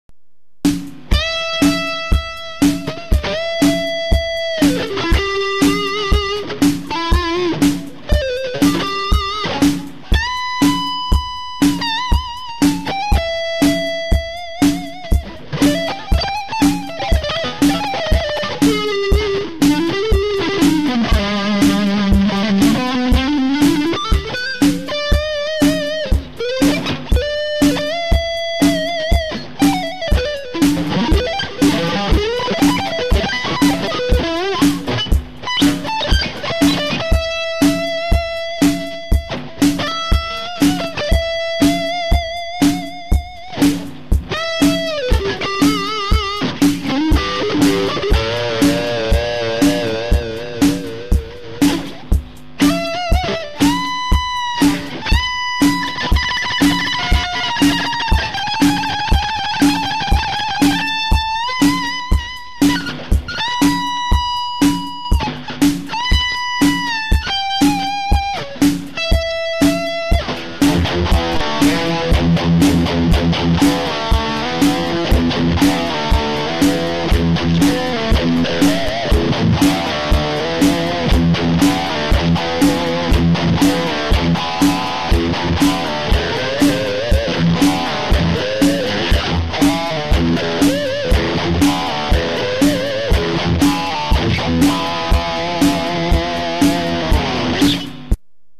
今回は取り合えず、ラインです。
一番、音は生々しい、デジタルくささが少ない。
その反面・・・サスティンの減退が早い・・・・・。
下に私のヘタなギターによるデモを貼っておきます。
取り合えず、メタル系歪みで、アイアンメイデンの
ケンタウルスシュミレーションを１時位のツマミでかけています。
使用ギターはフェンダージャパンのストラト、ＳＴ－７１ＴＸです。
あまり激しくビブラートをかけていません。
音源はシングルコイルですが、ＬＰで弾いたら